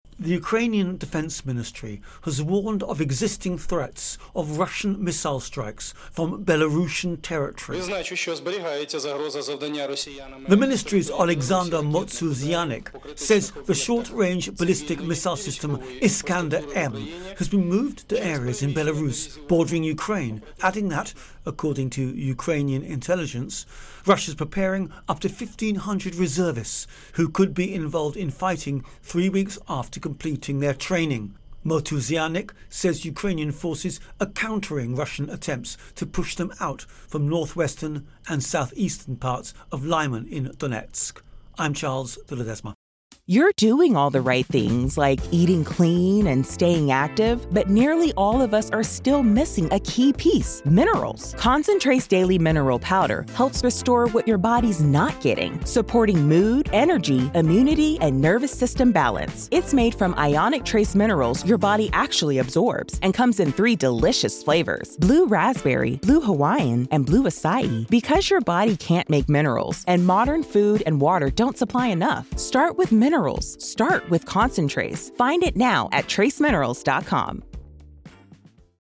Russia Ukraine War Belarus Intro and Voicer